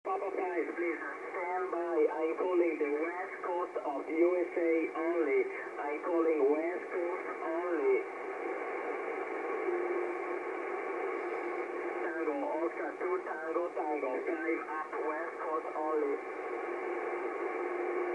calling West Coast USA only